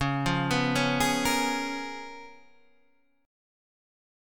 Db13 chord